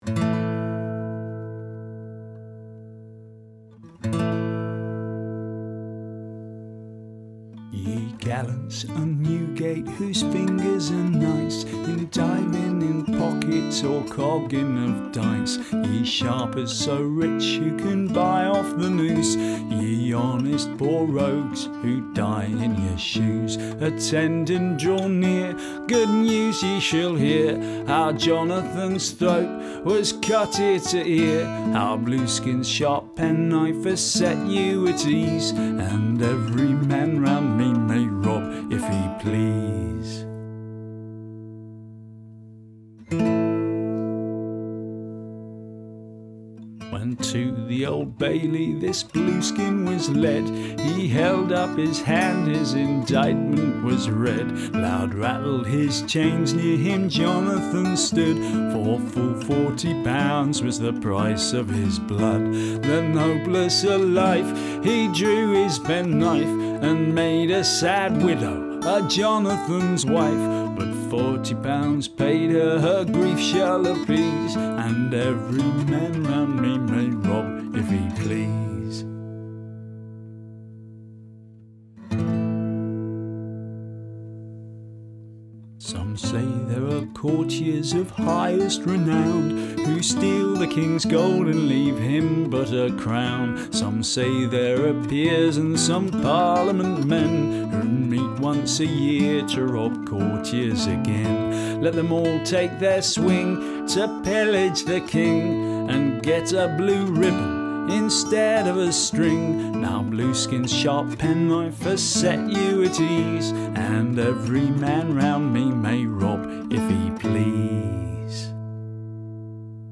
Murder Ballad